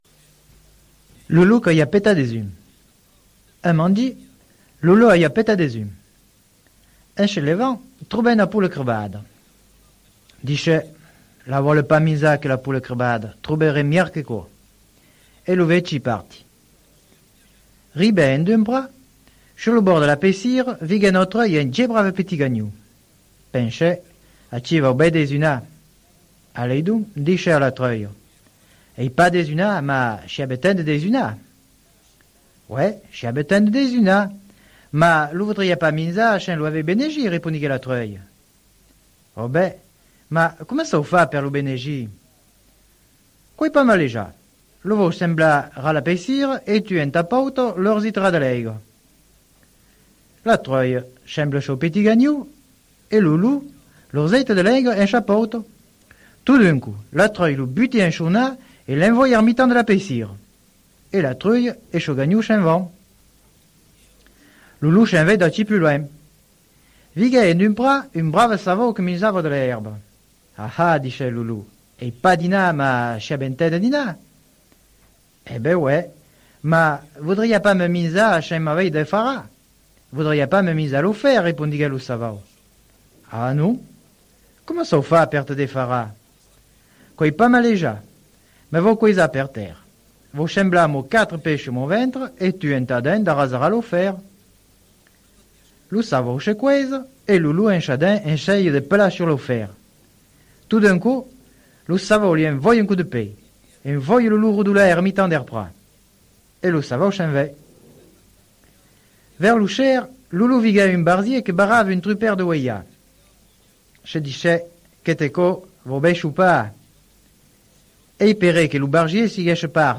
Parlar de Treinhac (Corrèsa).